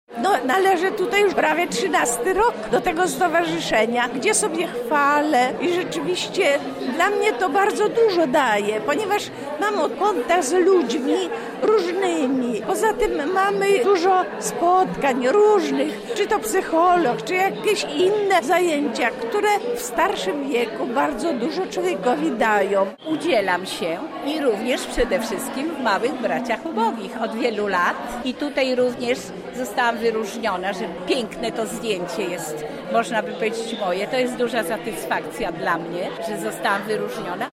Nasza reporterka rozmawiała z bohaterami dzisiejszego wernisażu.